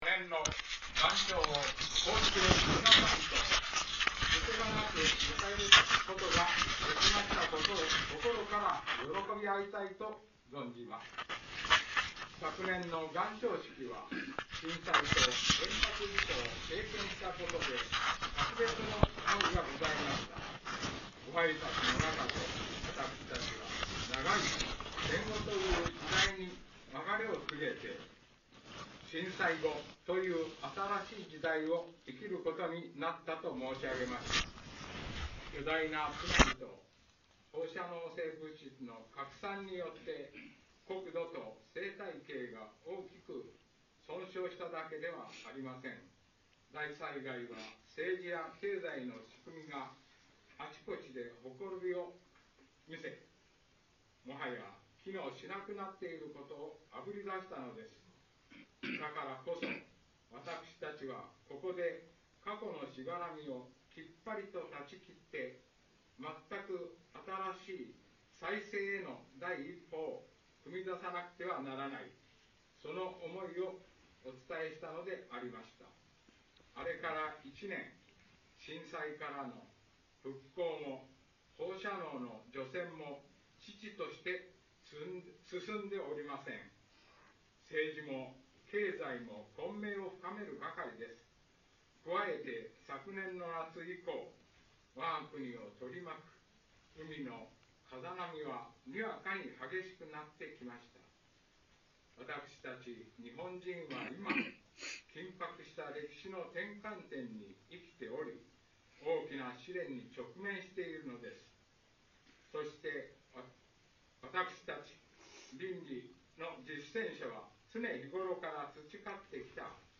元朝式(H25)
しかし年頭の辞は正常で新年の初めに相応しい清々しものであった。初めに東北大震災に続く原発事故の件や近々の政局転換の件の前置きの後に本題の話しがあった。さて本題は去年も少し触れた経済体系を優先するあまりの矛盾である。
元朝式h25.mp3